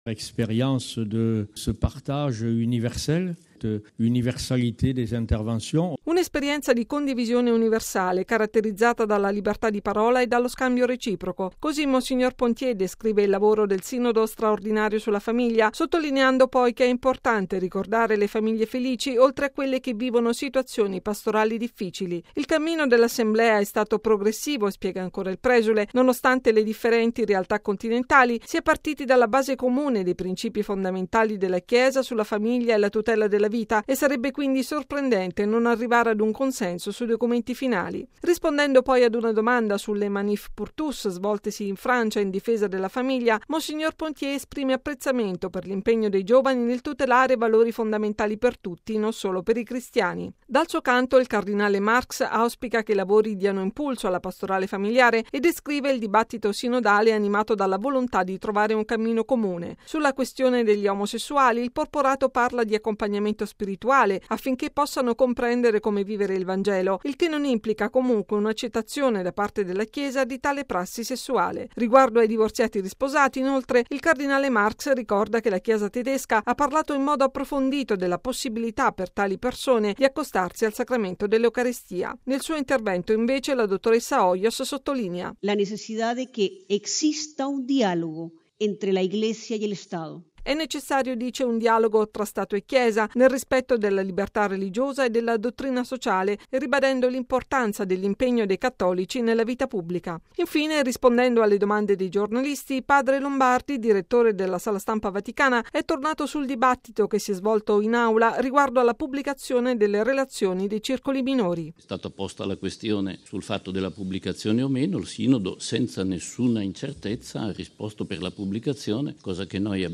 Il Sinodo straordinario sulla famiglia lavora, oggi, alla stesura dei documenti finali, che verranno presentati e votati in Aula domani. Condivisione universale e libertà di parola: così mons. Georges Pontier, presidente dei vescovi francesi, ha definito i lavori sinodali, durante il consueto briefing nella Sala Stampa vaticana.